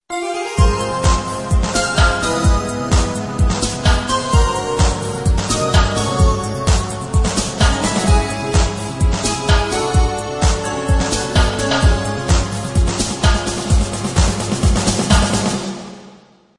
颁奖时的背景音效